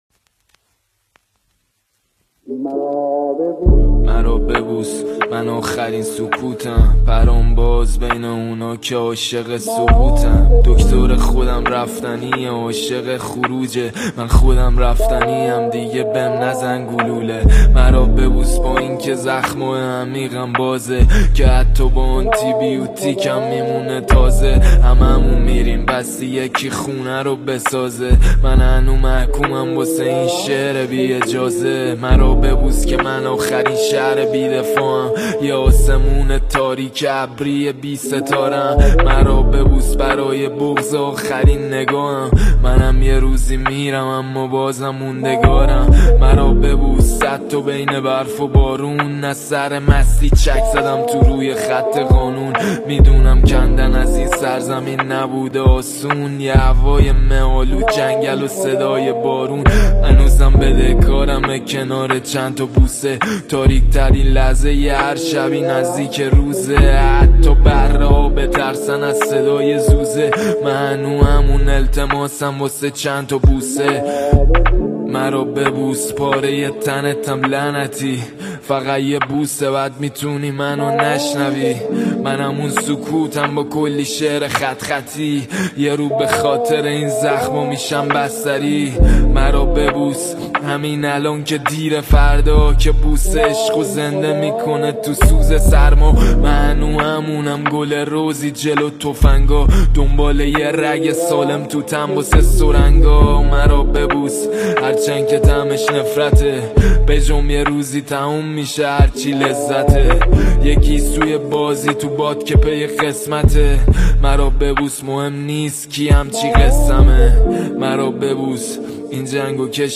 رپ فارسی های جدید